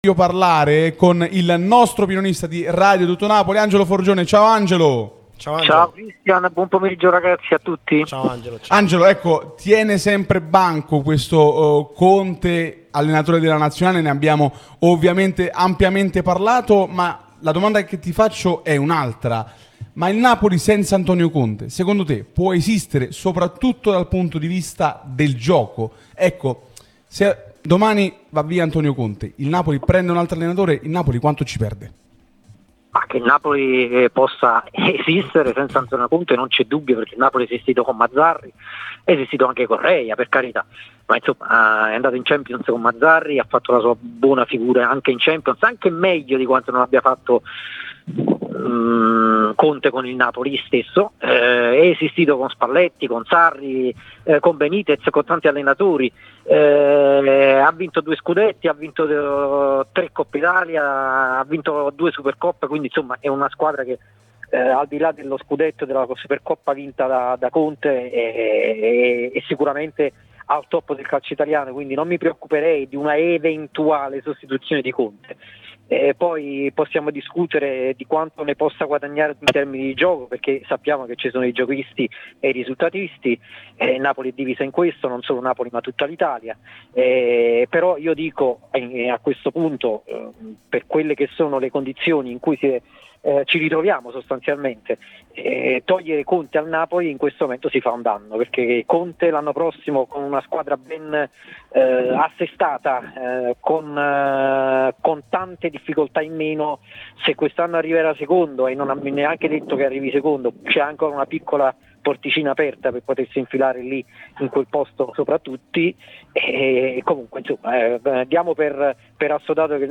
l'unica radio tutta azzurra e live tutto il giorno